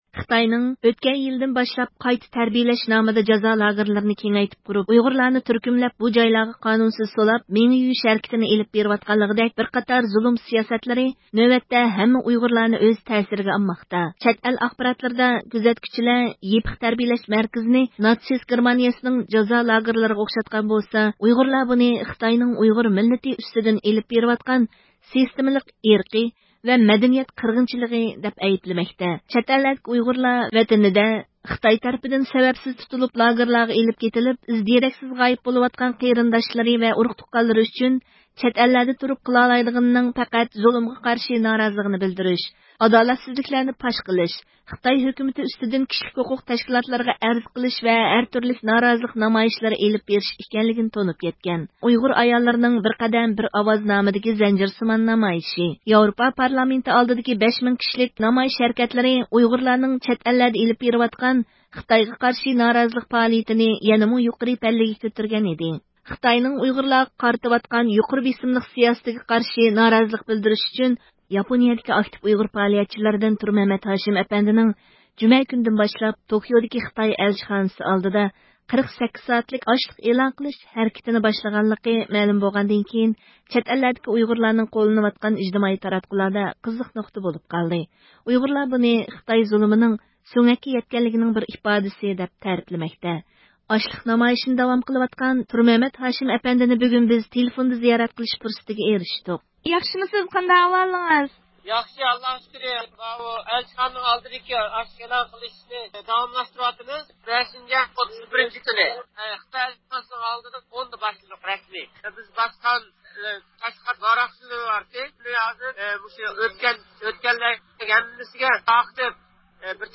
بۈگۈن بىز تېلېفوندا زىيارەت قىلىش پۇرسىتىگە ئېرىشتۇق.